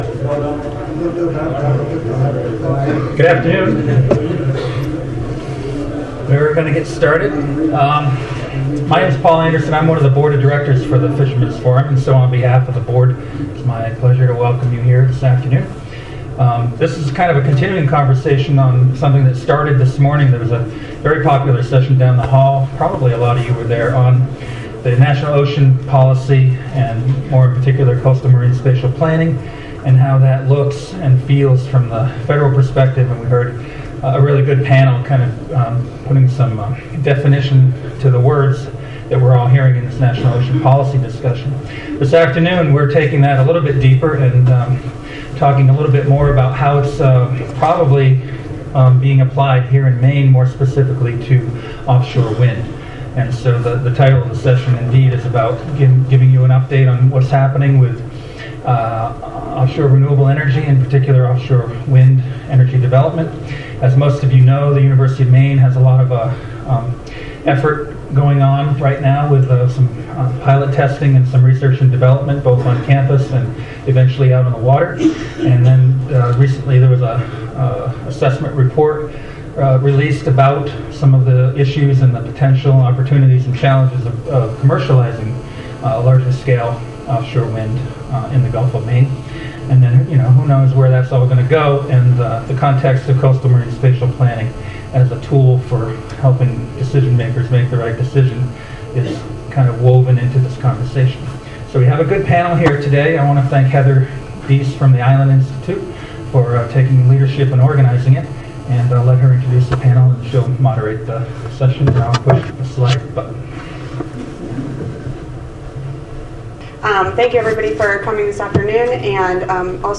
Maine Fishermen's Forum's Ocean Wind Exploitation meetings 2009 - 2024